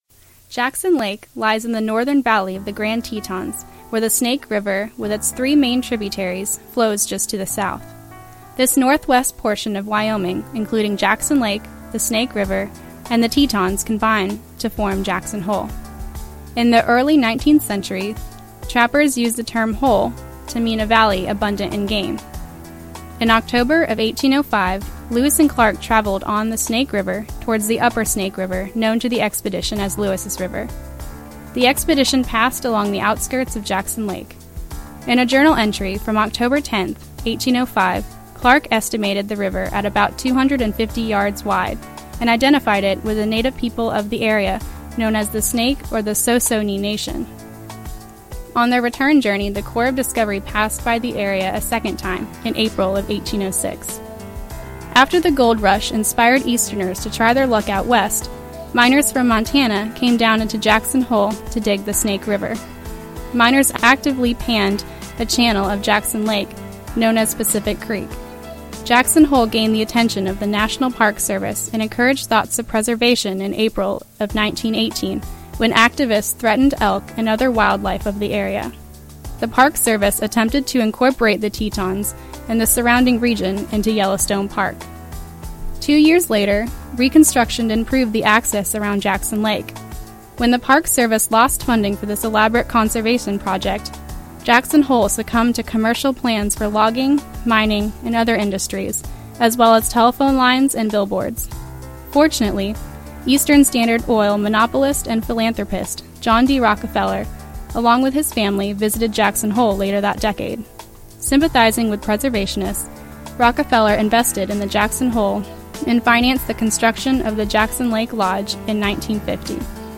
Researched, written, and narrated